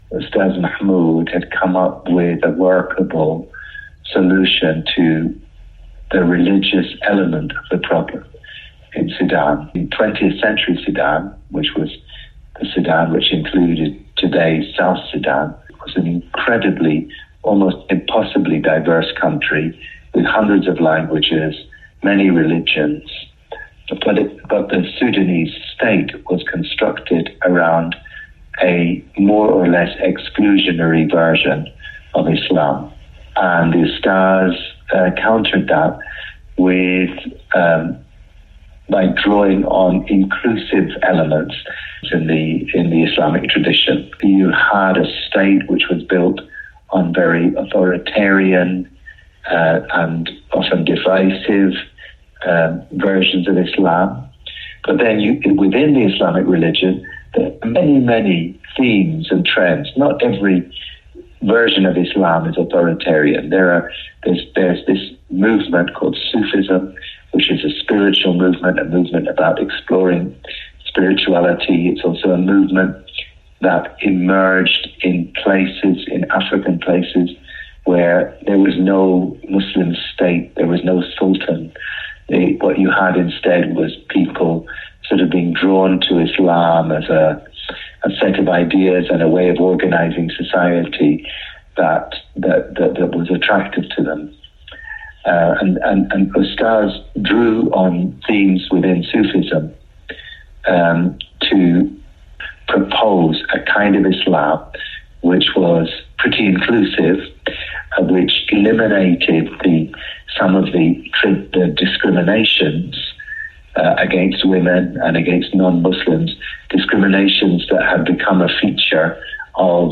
This interview was edited for brevity and clarity.